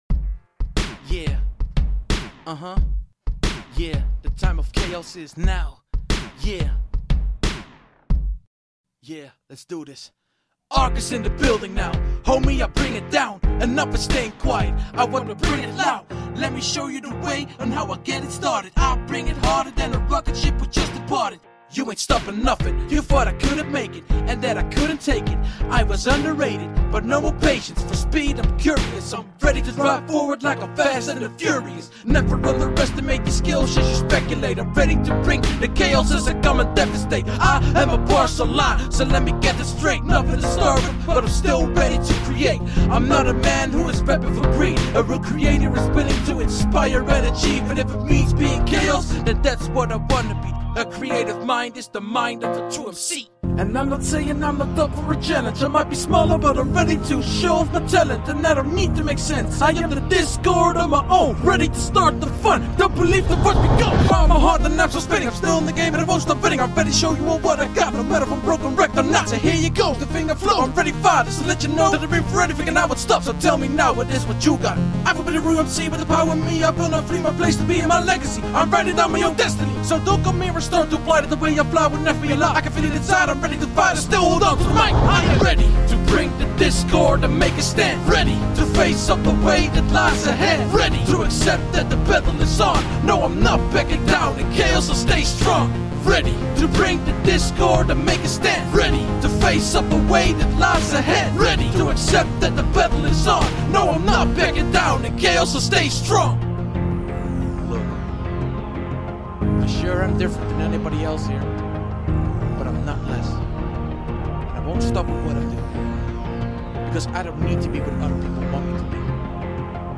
(An Re-master of an Old track I made.
A Rap song